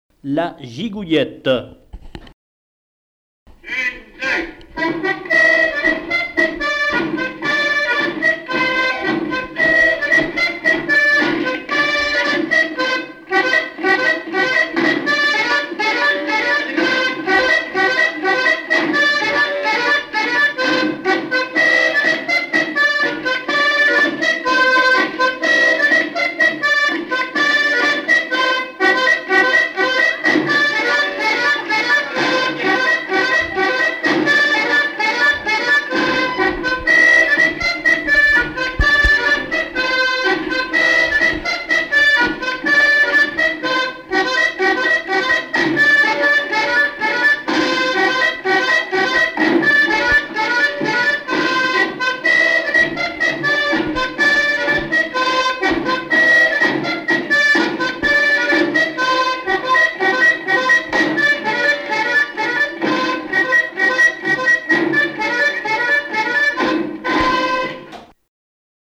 Localisation Bournezeau
Thème : 1074 - Chants brefs - A danser
Fonction d'après l'informateur danse : gigouillette ;
Catégorie Pièce musicale inédite